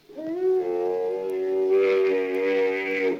c_camel_bat3.wav